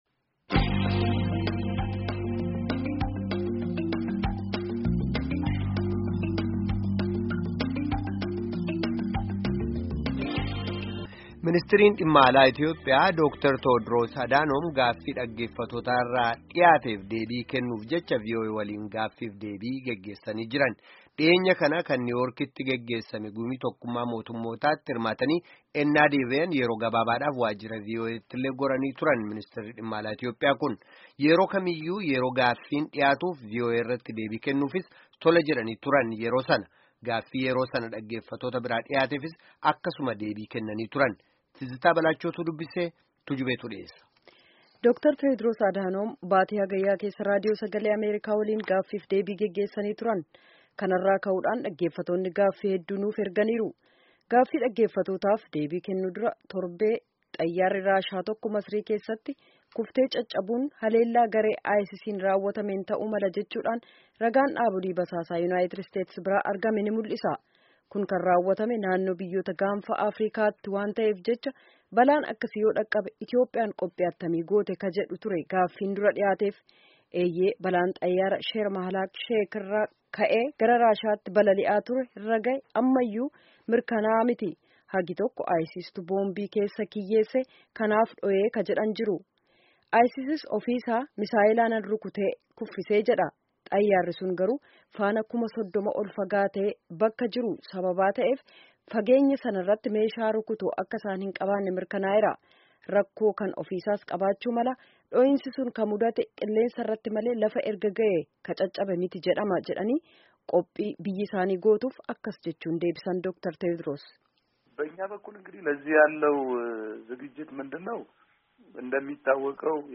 Gaffii fi deebii Dr. Tewdroos Adaanom waliin geggeessame dhaga'aa